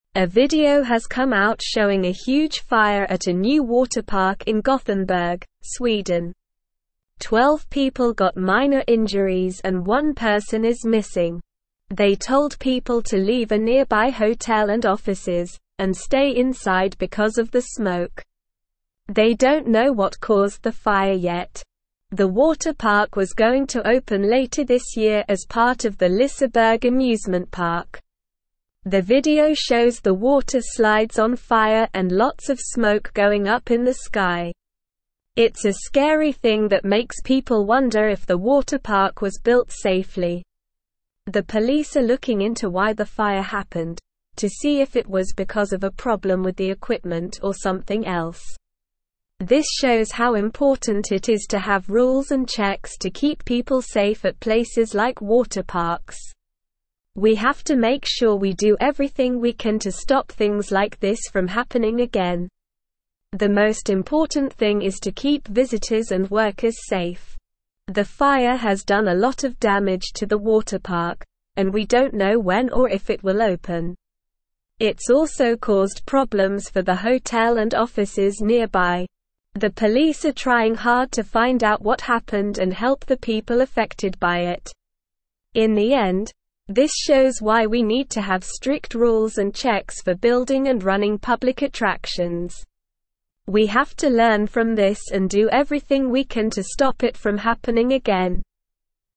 Slow
English-Newsroom-Upper-Intermediate-SLOW-Reading-Massive-Fire-Engulfs-Newly-Built-Water-Park-in-Sweden.mp3